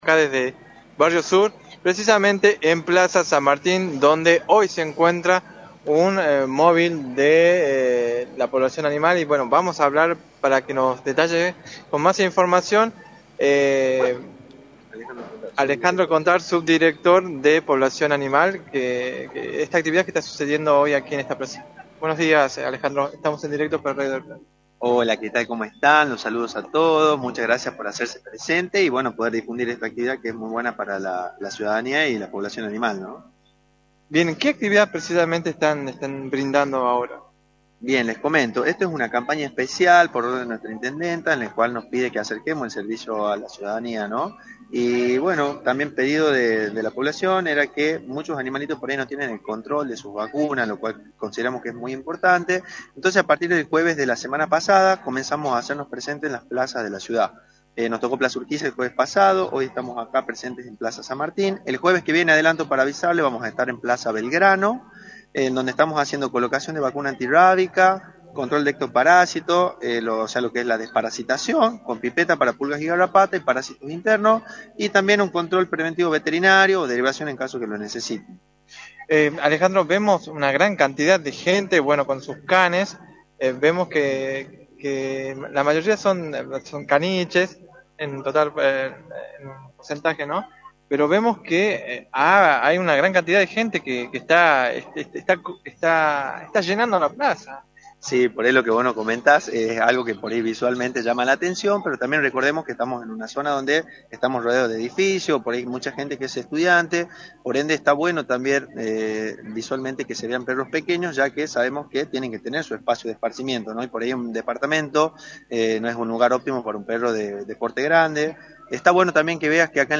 “Esto es una campaña especial que realizamos a pedido de la Intendente para acercar este servicio a la comunidad ya que muchos animales no siempre tienen el control correcto sobre sus vacunas, por lo que, hasta fin de año, todos los jueves vamos a estar en una plaza realizando esta campaña de colocación de vacunas, desparasitación y chequeo veterinario” señaló Contar en “La Mañana del Plata”, por la 93.9.